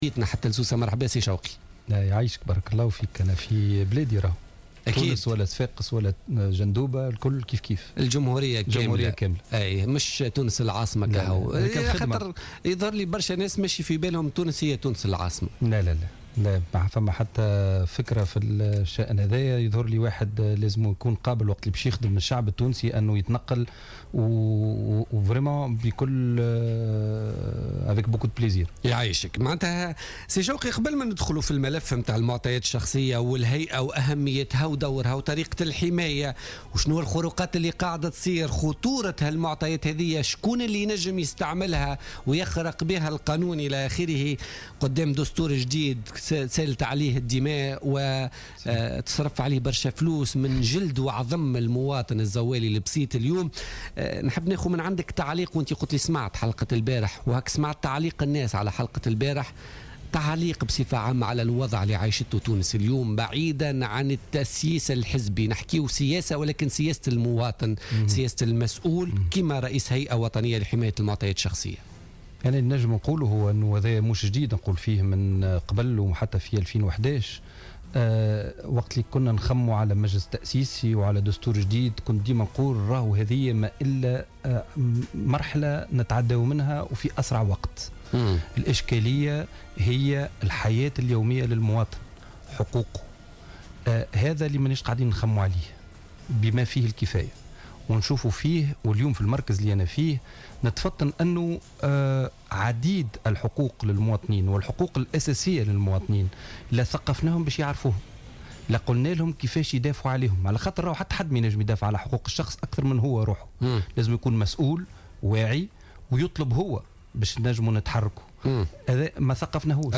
أكد شوقي قداس رئيس الهيئة الوطنية لحماية المعطيات الشخصية ضيف بوليتيكا اليوم الثلاثاء 5 جانفي 2015 ضرورة أن يتم توعية المواطن بأهمية حماية معطياته الشخصية التي تعد من أول حقوقه.